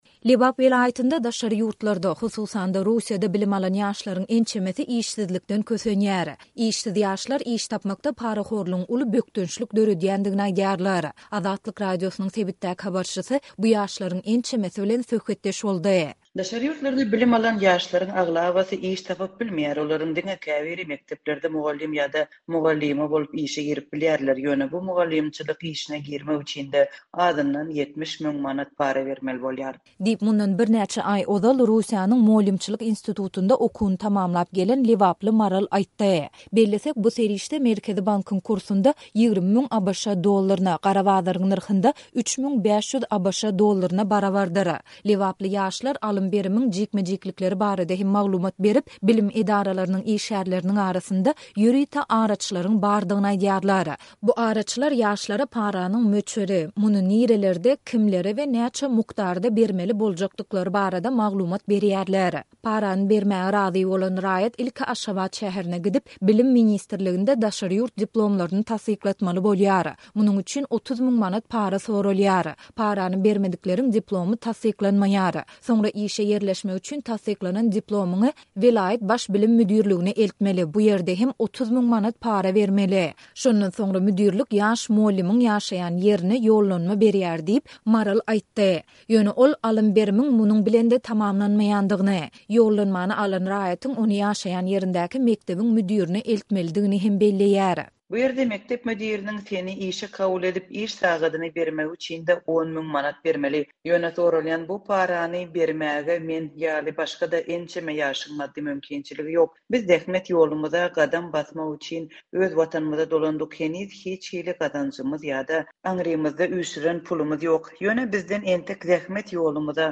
Lebap welaýatynda daşary ýurtlarda, hususan-da, Russiýada bilim alan ýaşlaryň ençemesi işsizlikden kösenýär. Işsiz ýaşlar iş tapmakda parahorlugyň uly bökdençlik döredýändigini aýdýarlar. Azatlyk Radiosynyň sebitdäki habarçysy bu ýaşlaryň ençemesi bilen söhbetdeş boldy.